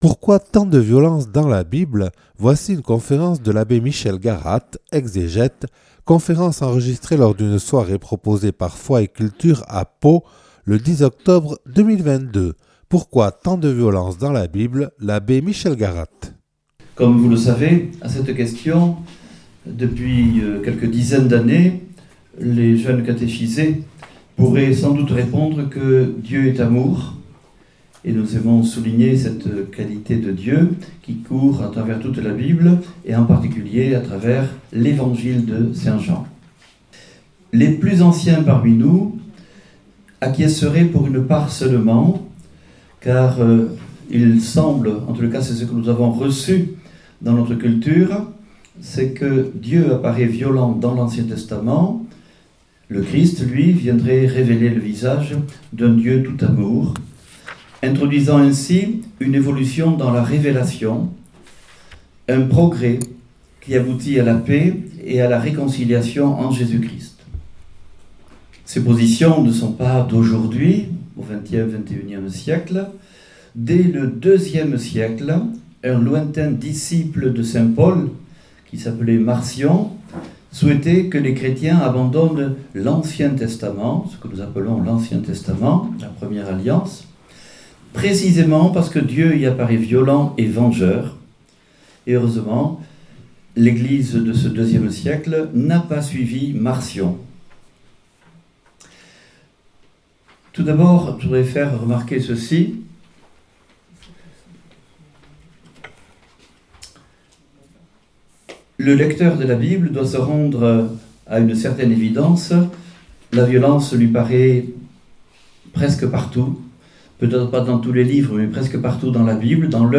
(Enregistrée le 10 octobre 2022 à Pau lors d’une soirée proposée par « Foi et Culture »)